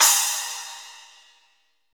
CYM SPLASH01.wav